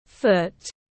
Foot /fʊt/
Foot.mp3